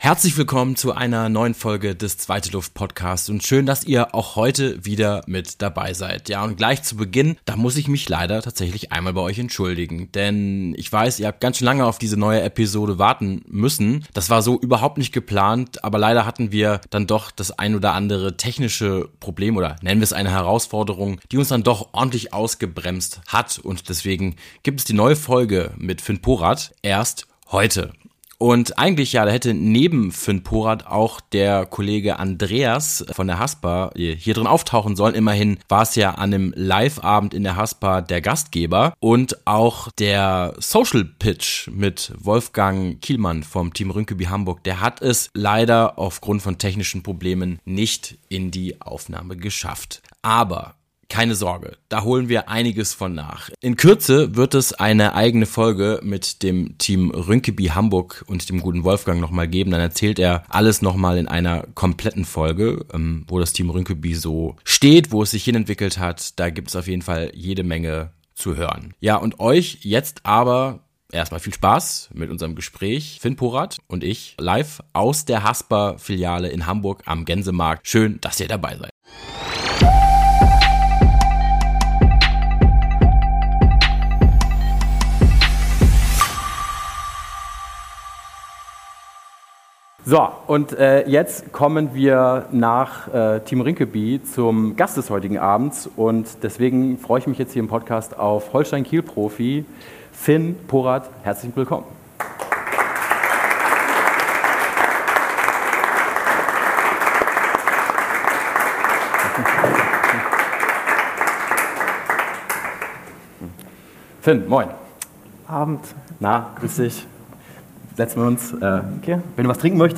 Beschreibung vor 11 Monaten In unserer 50. Folge feiern wir ein ganz besonderes Jubiläum – und das gemeinsam mit Holstein Kiel Profi Finn Porath! Live aus der Haspa-Filiale am Gänsemarkt sprechen wir mit Finn über seinen Weg zum Profifußball, Höhen und Tiefen seiner Karriere und den aktuellen Nicht-Abstiegstraum mit Holstein Kiel.